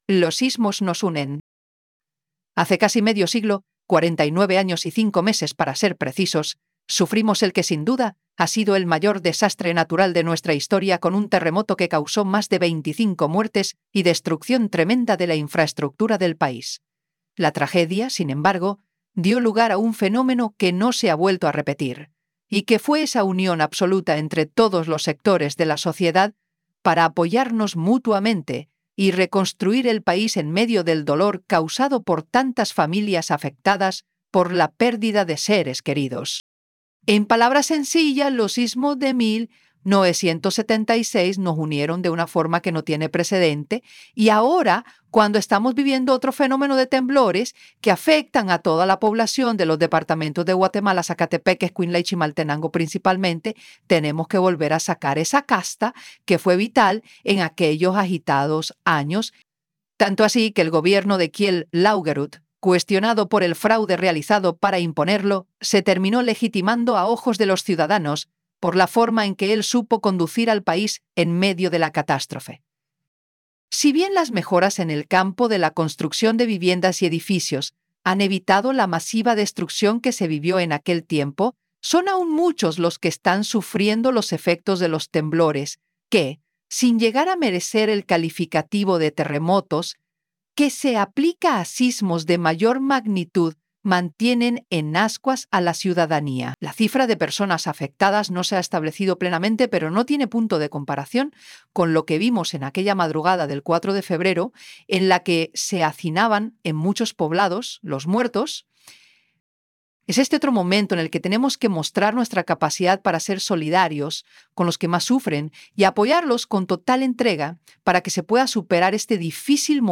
PlayAI_Los_sismos_nos_unen-Editorial.wav